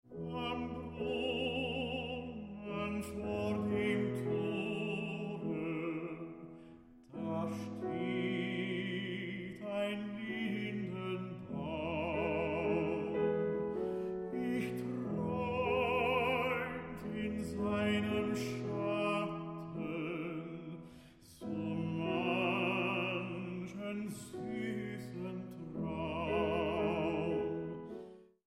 Tenor
Piano
This studio recording